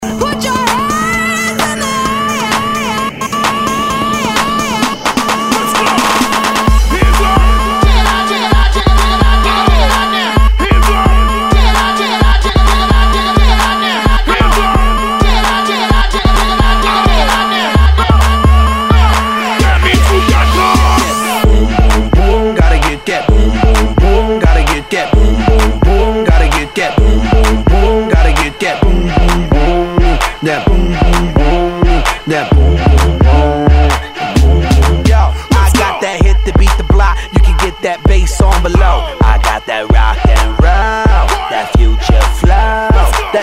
Tag       HIP HOP HIP HOP